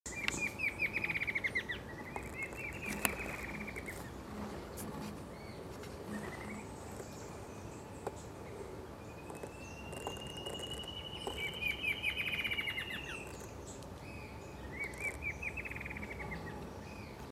Rufous-margined Antwren (Herpsilochmus rufimarginatus)
Life Stage: Adult
Location or protected area: Parque Nacional Iguazú
Condition: Wild
Certainty: Recorded vocal
MVI_7200-TILUCHI-ALA-ROJIZA.mp3